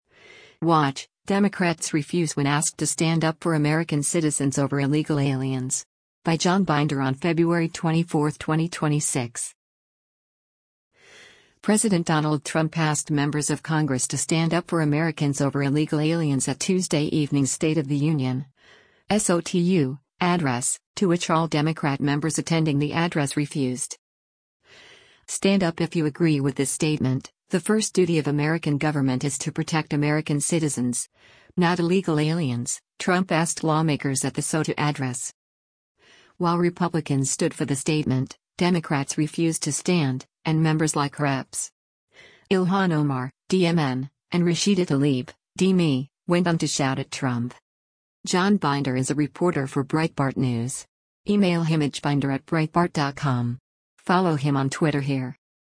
President Donald Trump asked members of Congress to stand up for Americans over illegal aliens at Tuesday evening’s State of the Union (SOTU) address, to which all Democrat members attending the address refused.
While Republicans stood for the statement, Democrats refused to stand, and members like Reps. Ilhan Omar (D-MN) and Rashida Tlaib (D-MI) went on to shout at Trump.